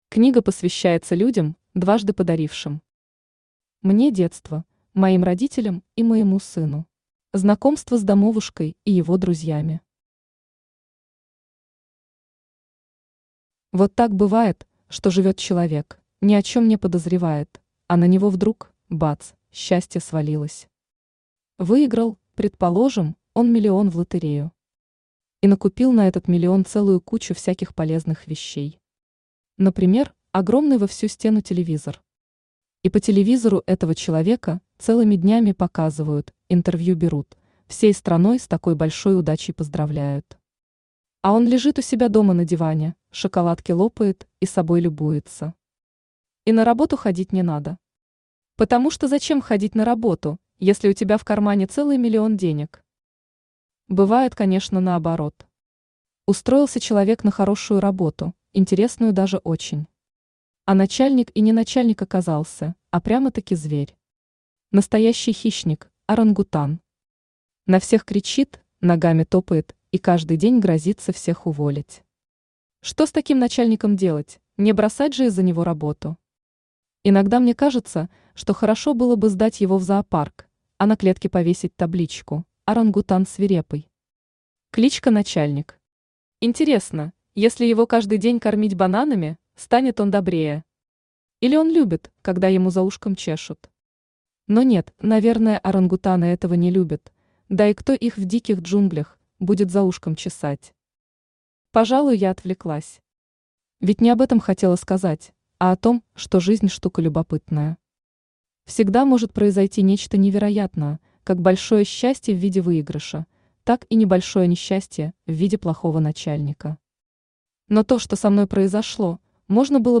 Аудиокнига Домовушка | Библиотека аудиокниг
Aудиокнига Домовушка Автор Ольга Владимировна Манько Читает аудиокнигу Авточтец ЛитРес.